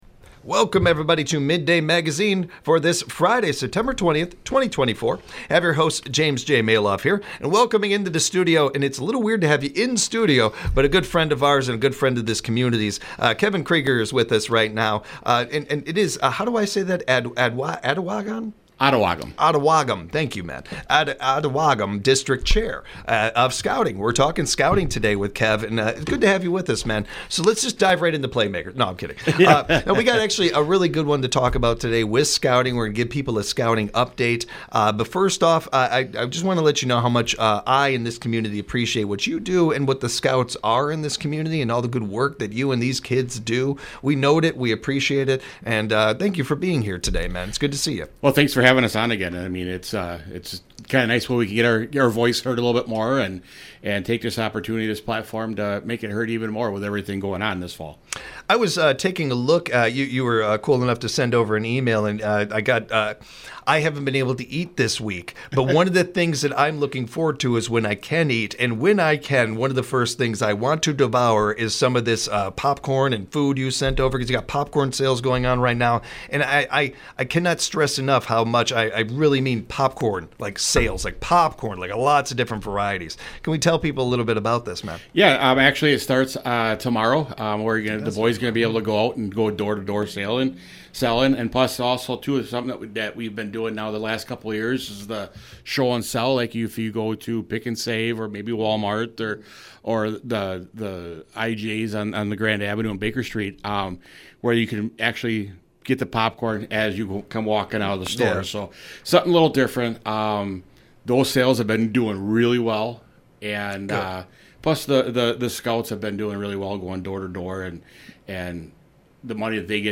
Mid-day Magazine gives you a first look into what’s happening in the Central Wisconsin area. WFHR has a variety of guests such as non-profit organizations, local officials, state representatives, event coordinators, and entrepreneurs.